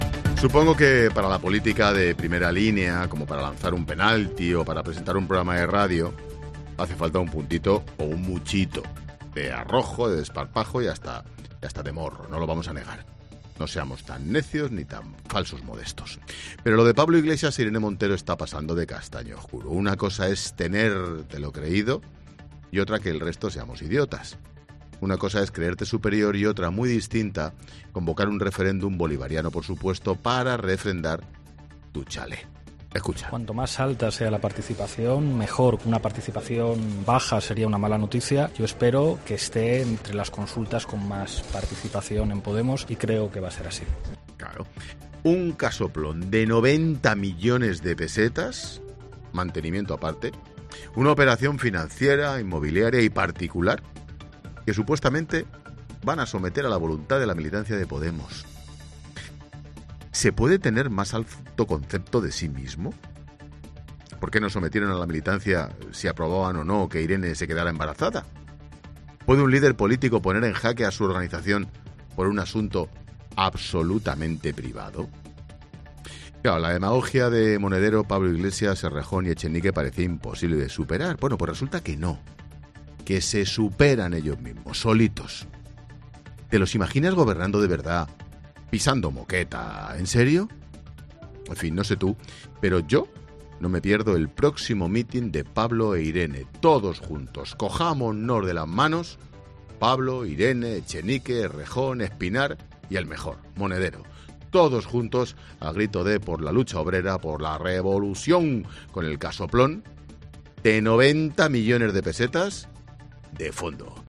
Monólogo de Expósito
Comentario de Ángel Expósito sobre las incoherencias del discurso de Podemos.